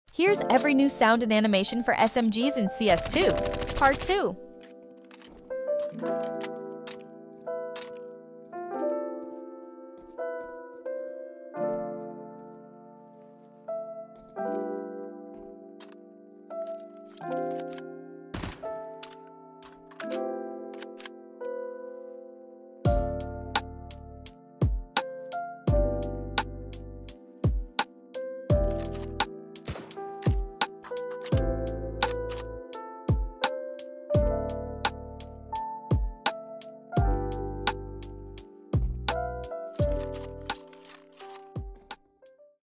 EVERY NEW SMG Animation and sound effects free download